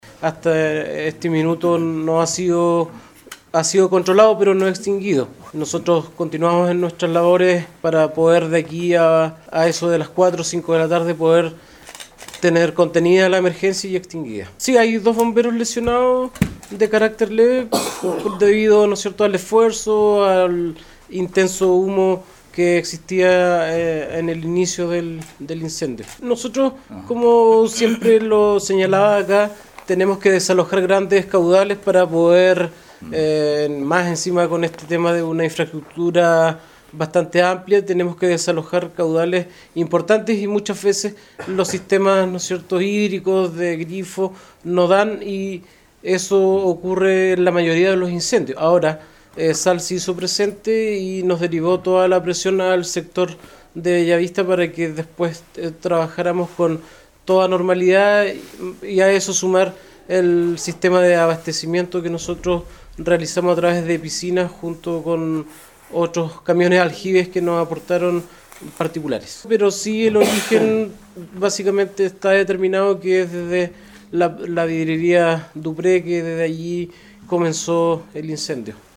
Desde Bomberos de Ancud se informó que durante este día jueves se continúan con las labores de extinción del incendio ya que persiste el humo y restos de las viviendas incandescentes y que se abrirá una investigación sobre el siniestro.